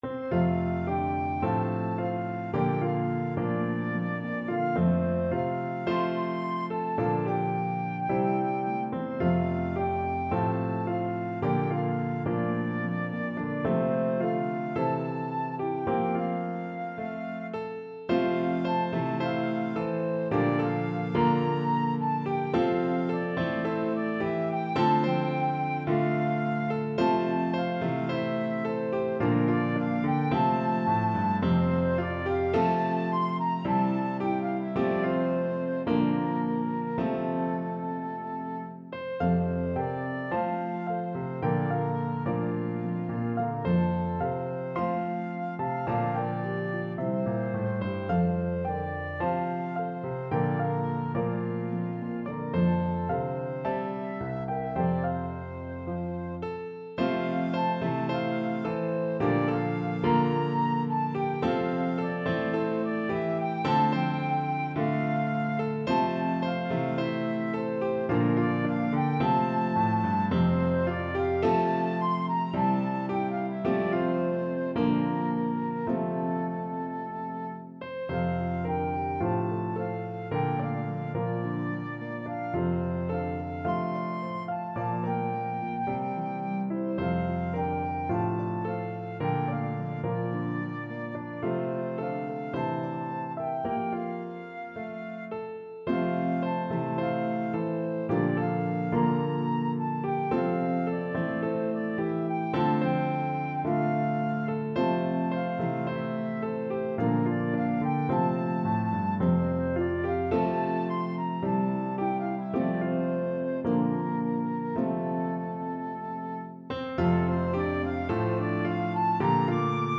pedal harp
Harp, Piano, and Flute version